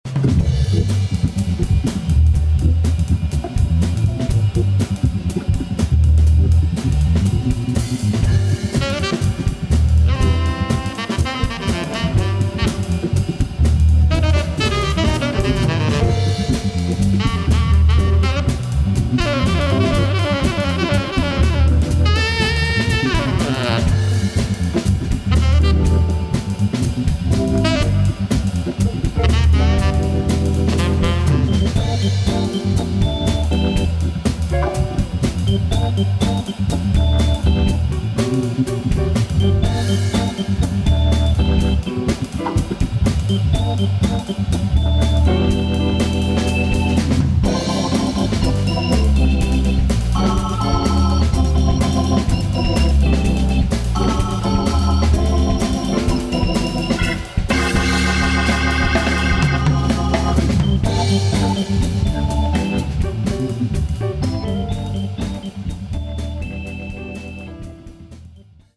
hammond